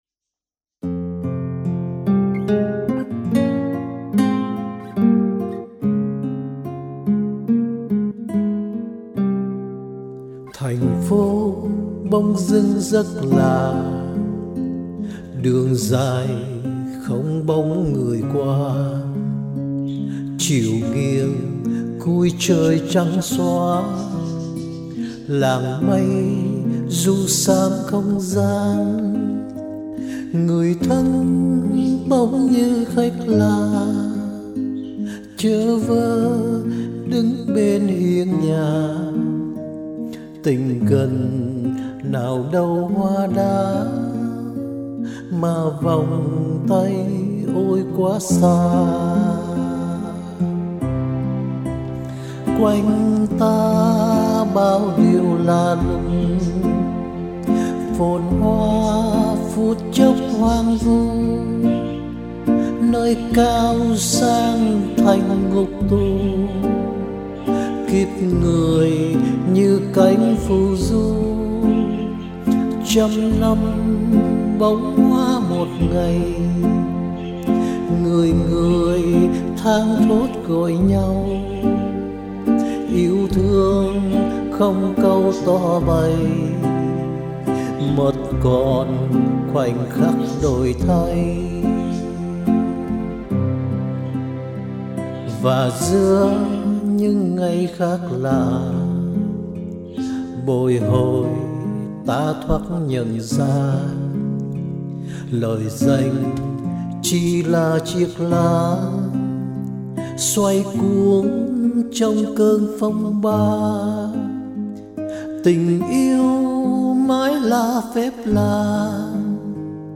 giọng hát trầm ấm